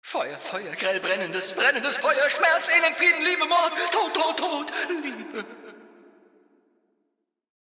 Datei:Maleadult01default freeformdc ffdcadamsmorgan 000cb529.ogg
Fallout 3: Audiodialoge
Charakter: Der Prediger